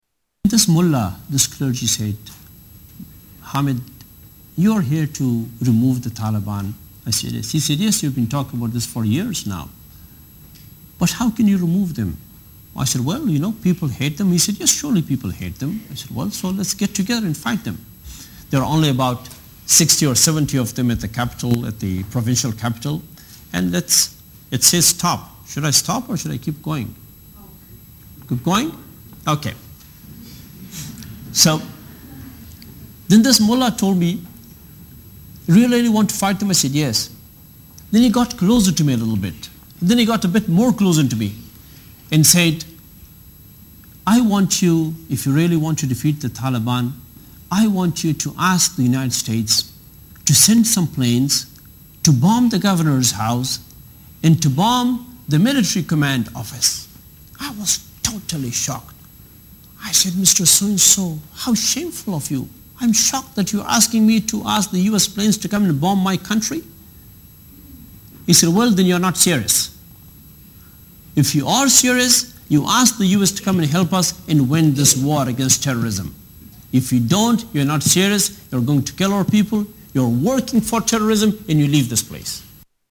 Tags: Political Hamid Karzai audio Interviews President Afghanistan Taliban